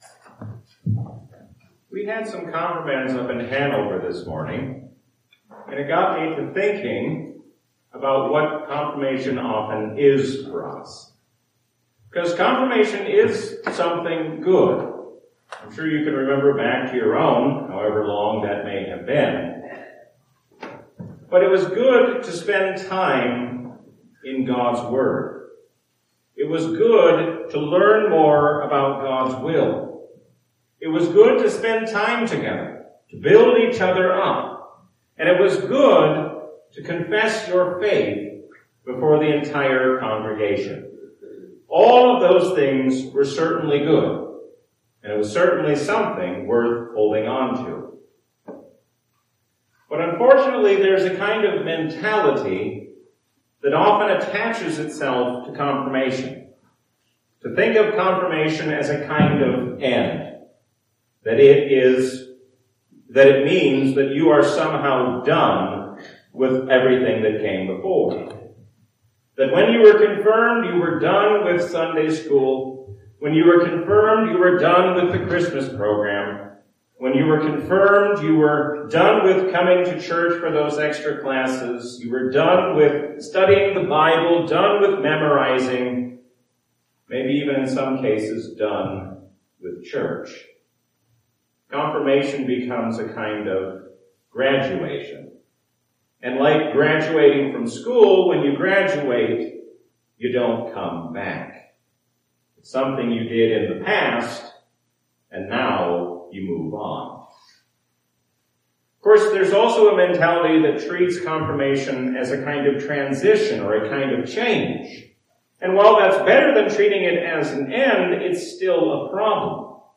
A sermon from the season "Lent 2025."